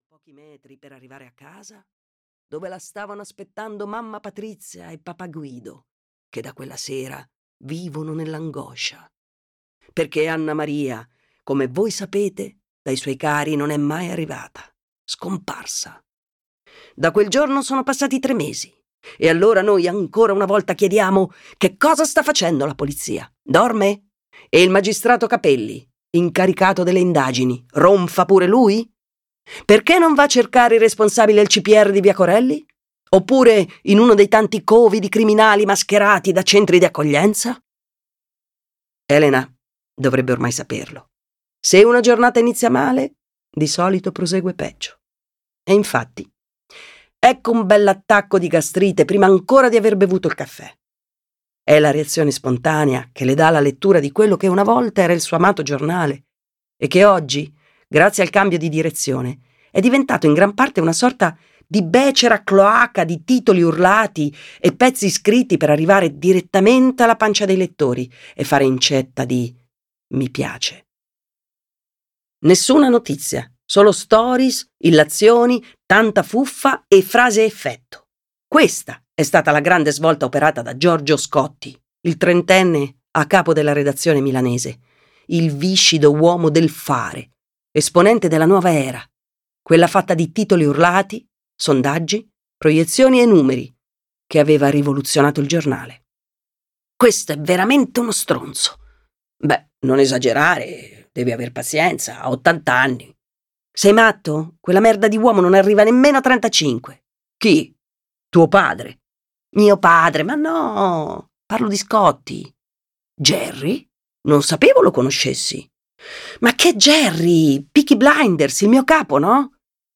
"Una parola per non morire" di Sandra Bonzi - Audiolibro digitale - AUDIOLIBRI LIQUIDI - Il Libraio
• Letto da: Lucia Mascino